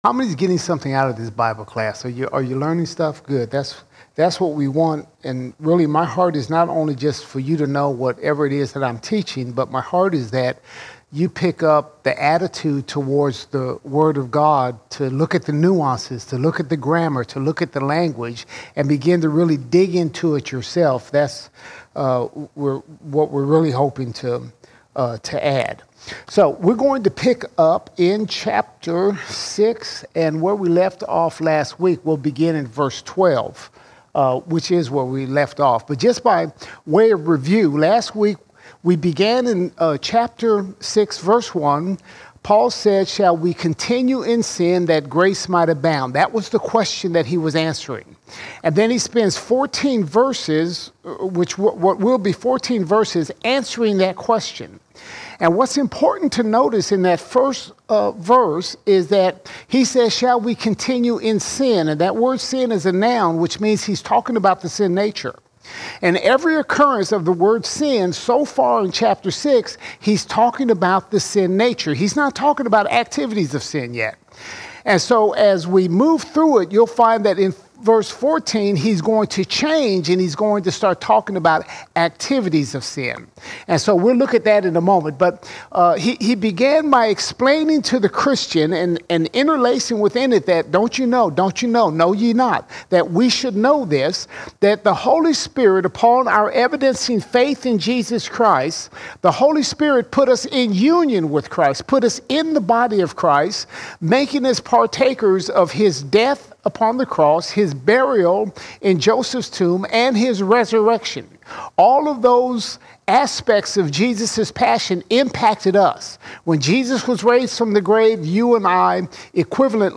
31 August 2023 Series: Romans All Sermons Romans 6:13 to 7:2 Romans 6:13 to 7:2 The cross is our power over the sin nature and the activities of sin that so easily beset us.